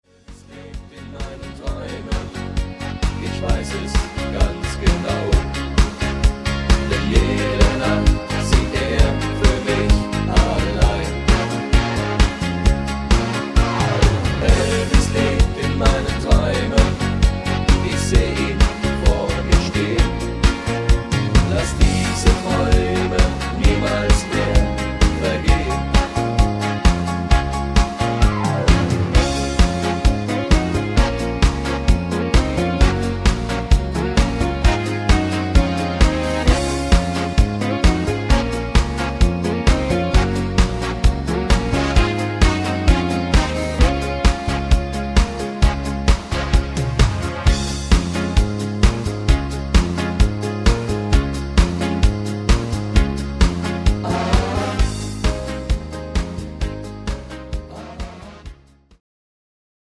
Rhythmus  Rock'n Roll
Art  Deutsch, Schlager 2010er